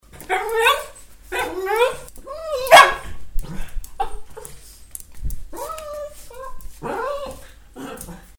Je chante !
loulouchante.mp3